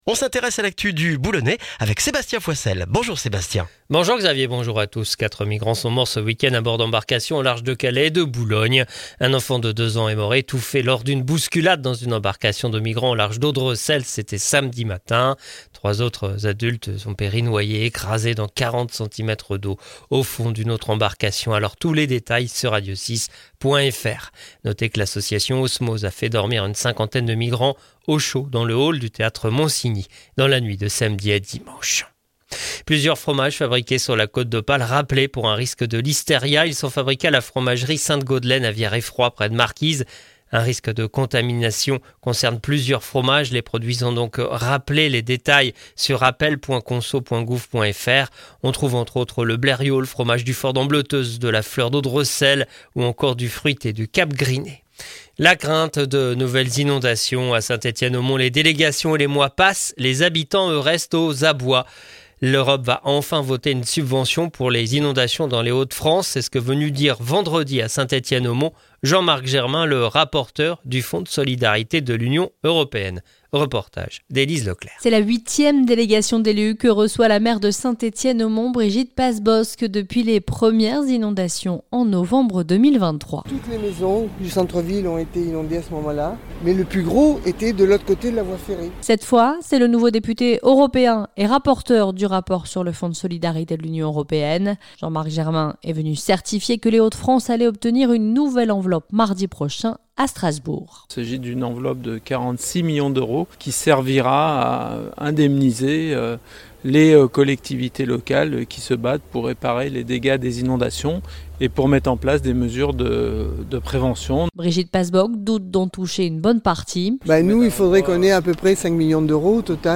Le journal du lundi 7 octobre dans le Boulonnais